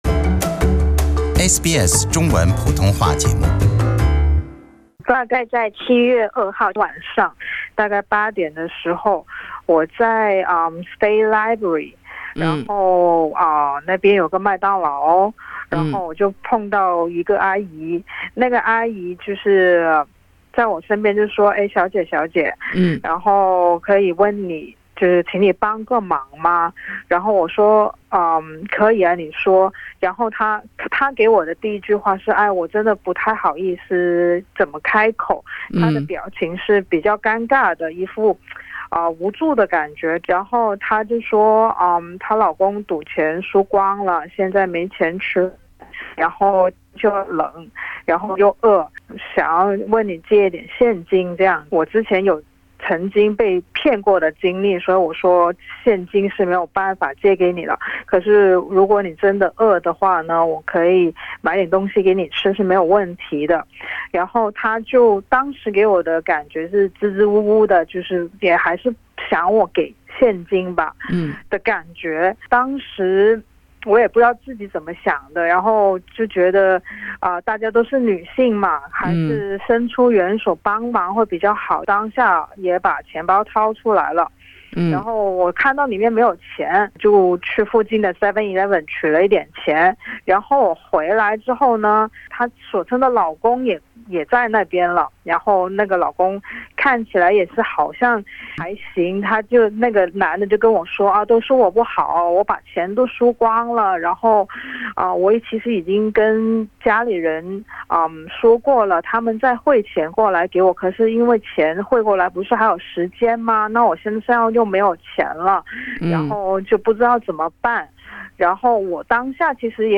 采访声音已做变声处理。